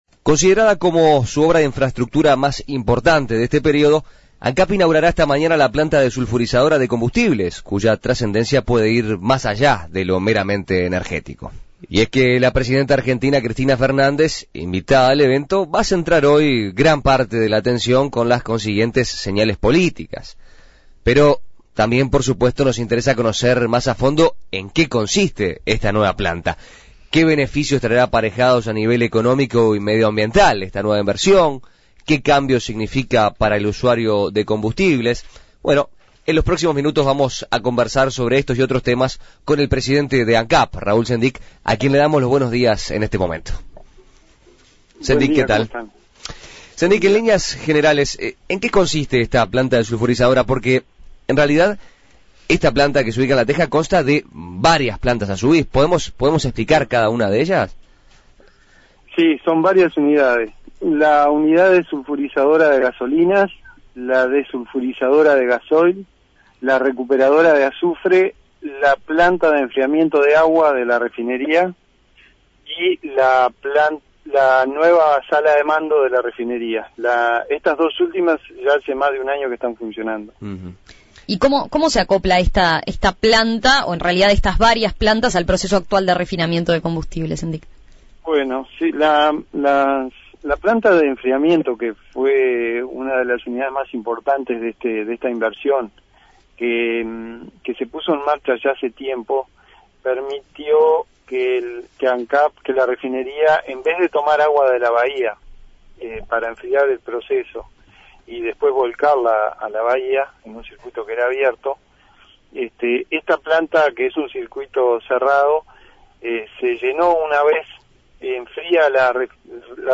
La decisión de la Intendencia de Montevideo de entregar las llaves de la ciudad a la presidenta argentina Cristina Fernández puso en segundo plano el motivo principal de su llegada: la inauguración de la planta desulfurizadora de Ancap. Para conocer qué significa esta inversión para el país, En Perspectiva conversó con el presidente del ente petrolero, Raúl Sendic.
Entrevistas